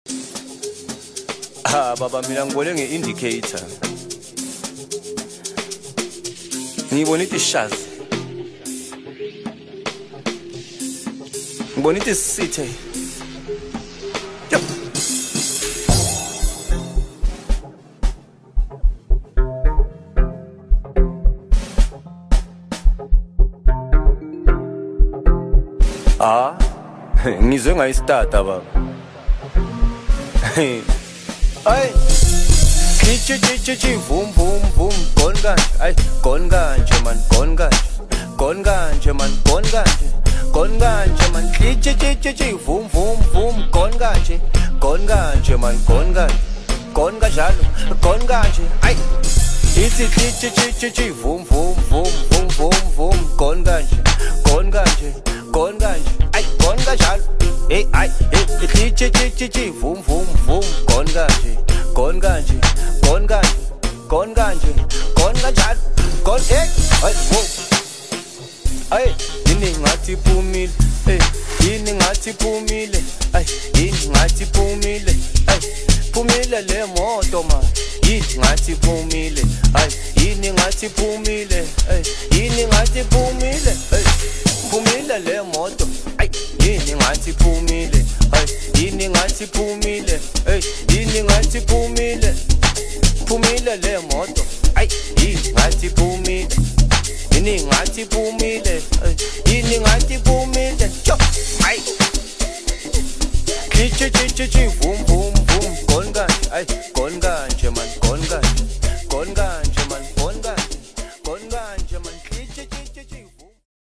Amapiano, DJ Mix, Hip Hop
South African singer-songwriter